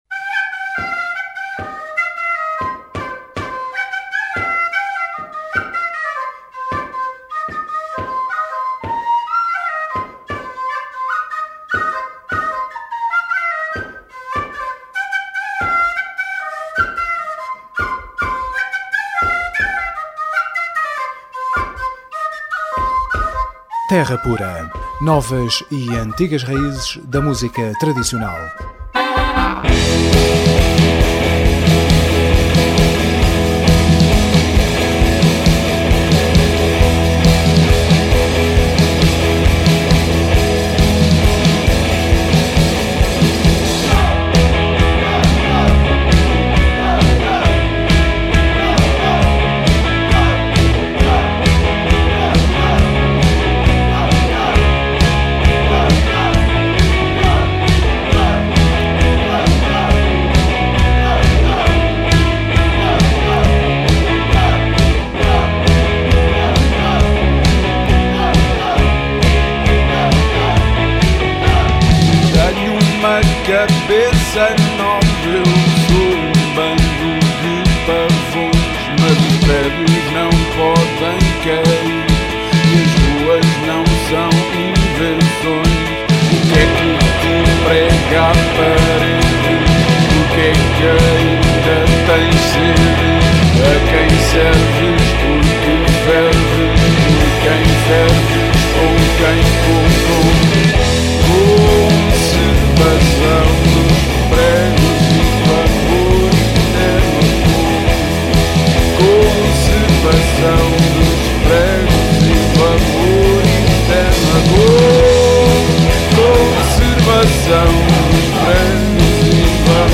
Terra Pura 27JUN11: Entrevista Os Velhos – Crónicas da Terra
Final de emissão com Os Golpes (convidados da próxima emissão), espécie de irmãos mais velhos d’Os Velhos.